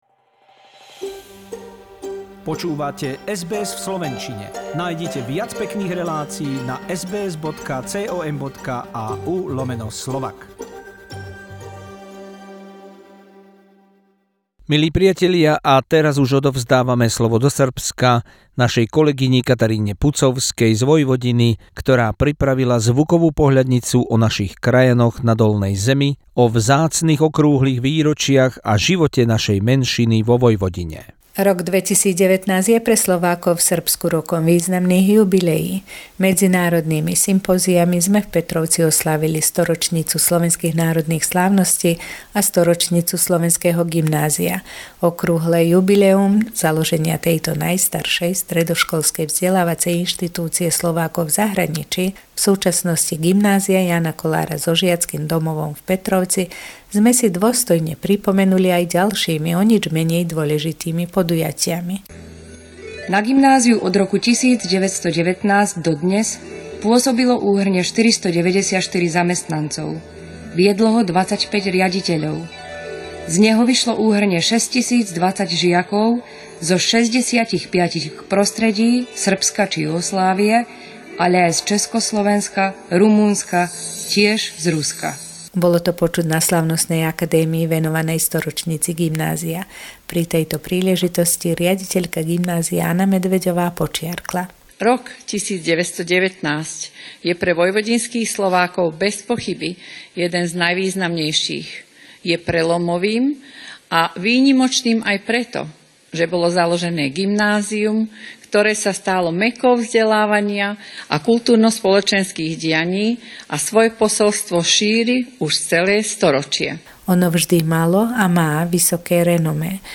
Stringer report